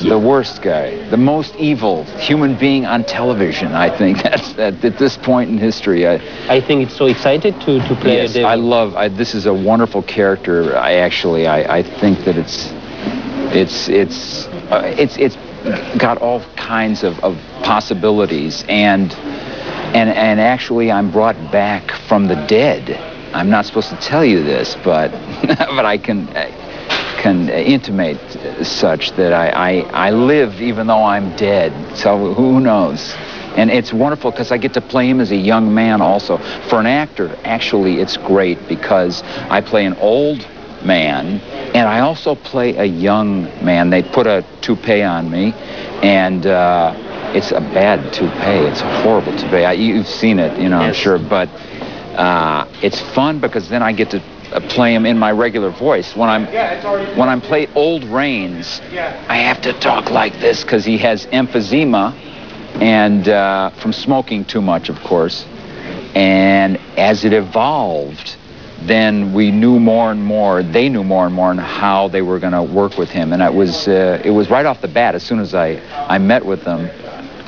Interview de Patrick Bauchau en V.O ( 842 Ko )